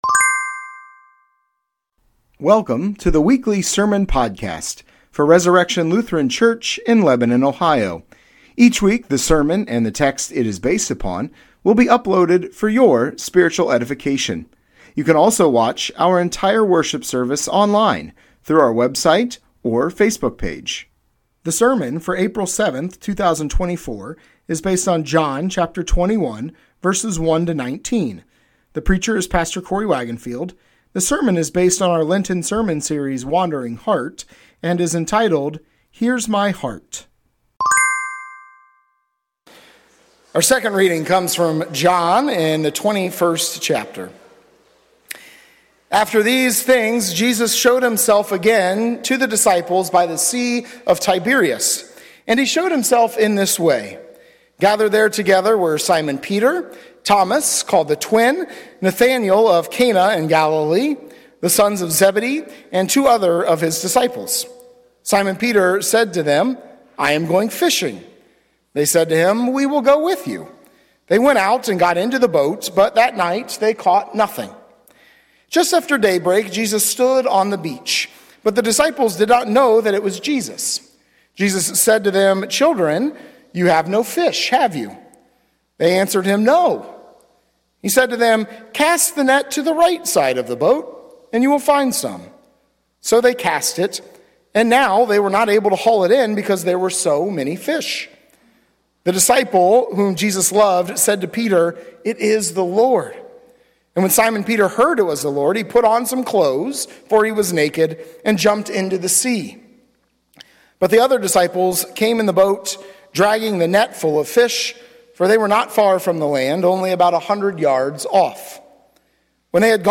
Sermon Podcast Resurrection Lutheran Church - Lebanon, Ohio April 7, 2024 - "Here's My Heart"